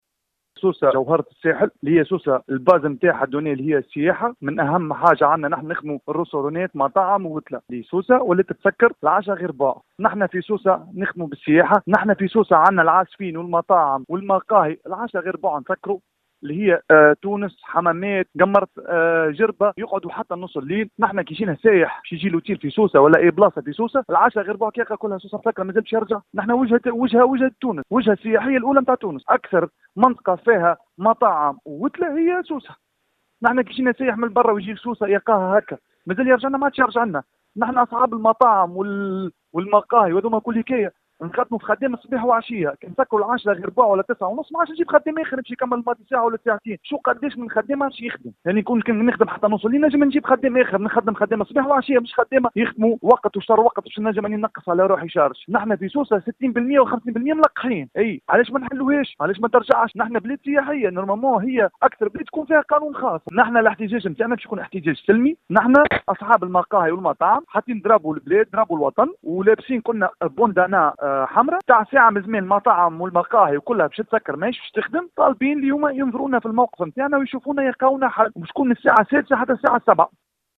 وفي تصريح للجوهرة أف أم